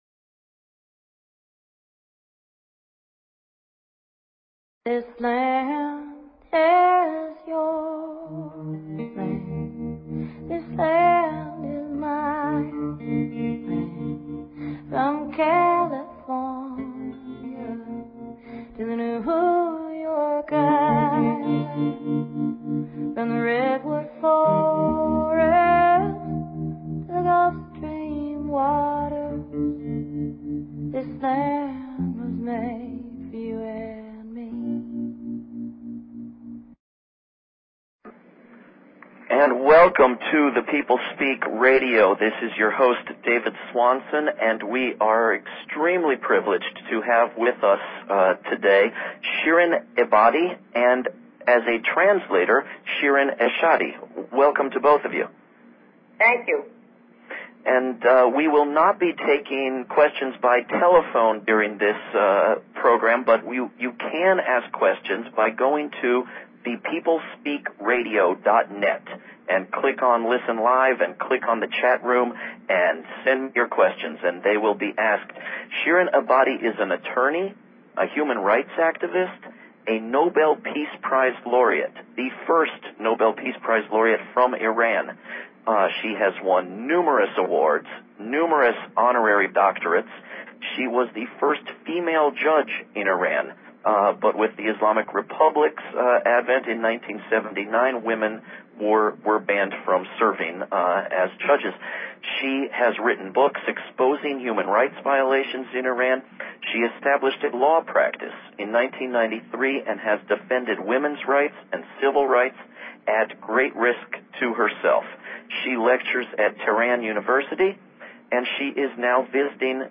Guest, Shirin Ebadi